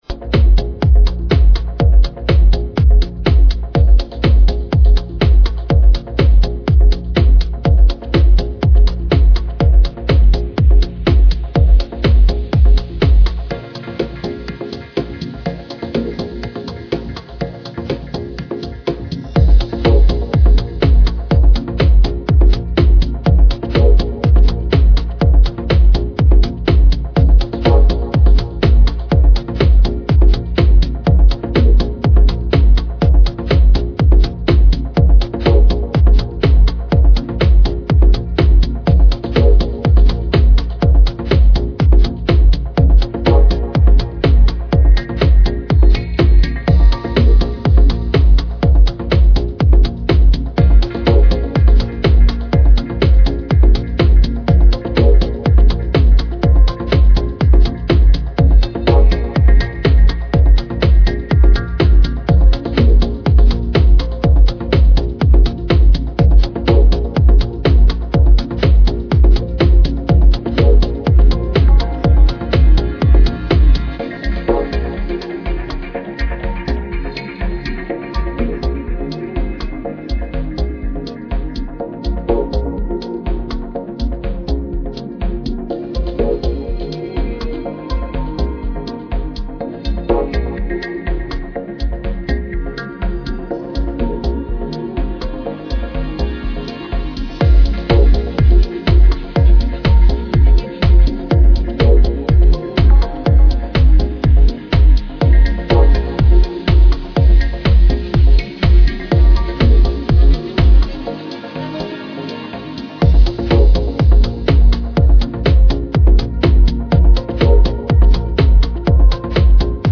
deep, moody synthesis and precise, punchy drums